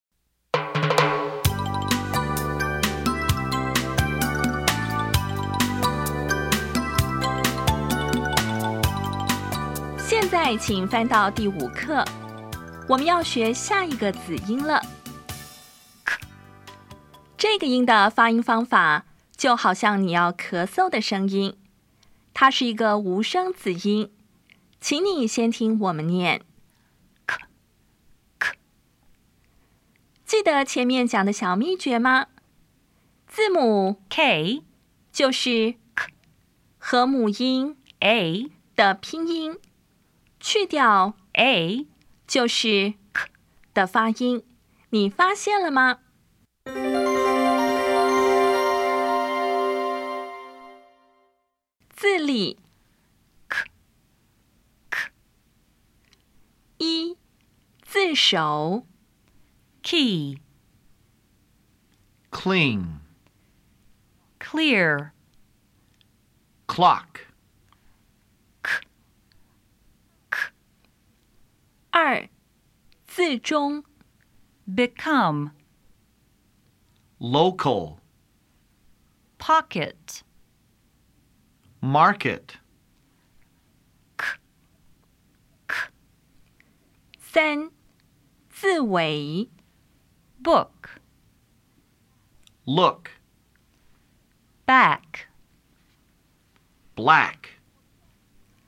当前位置：Home 英语教材 KK 音标发音 子音部分-1: 无声子音 [k]
音标讲解第五课
[ki]
[klin]